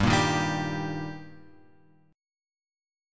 Gm#5 chord {3 1 1 0 x 3} chord